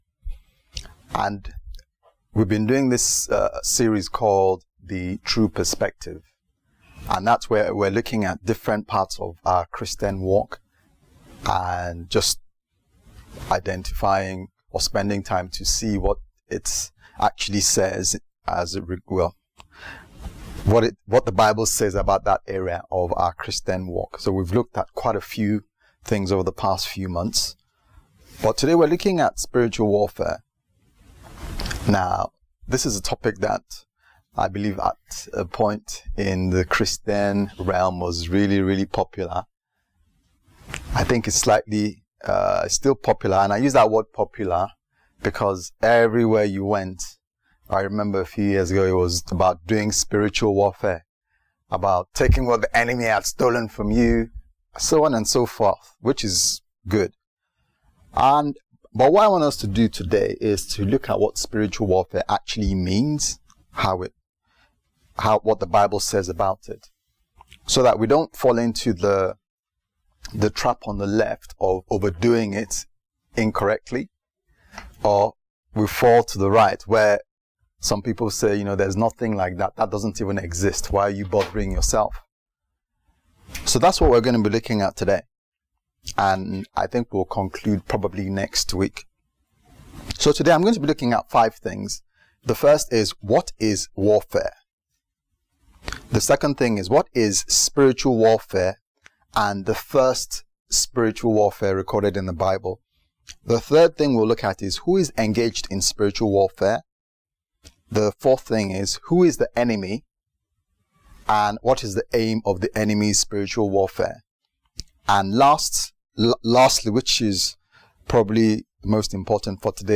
The True Perspective Service Type: Sunday Service « The True Perspective